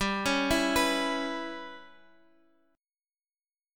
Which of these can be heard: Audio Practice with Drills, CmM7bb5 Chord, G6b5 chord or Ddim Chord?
G6b5 chord